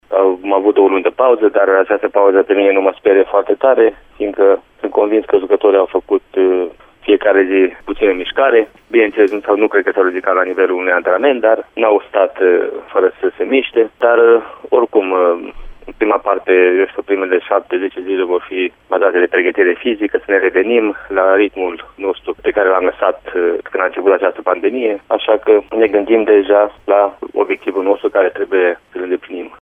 Dorinel Munteanu a subliniat din nou faptul că își poate recupera fără mari probleme jucătorii din punct de vedere fizic: